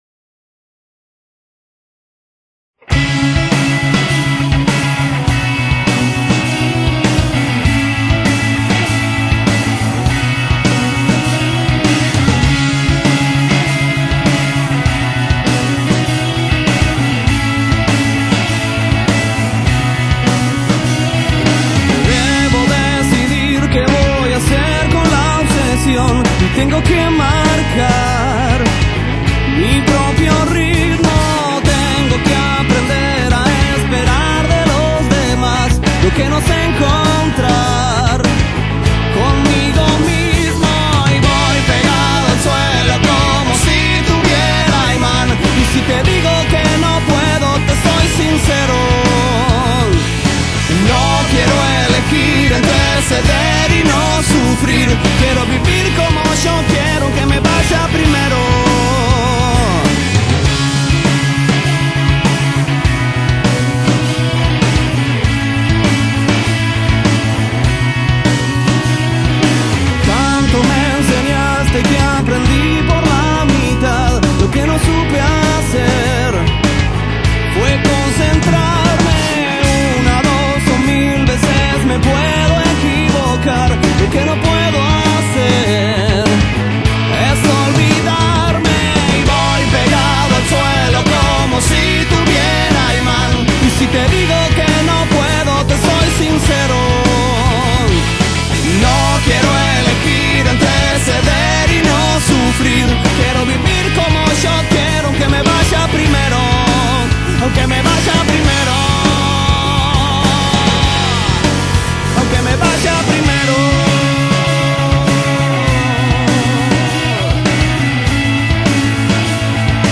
Carpeta: Rock uruguayo mp3